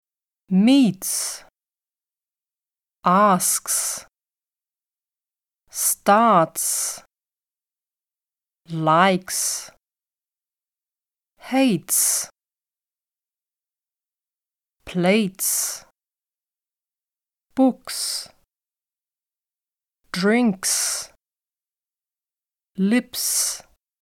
words-s-sound-voiceless.mp3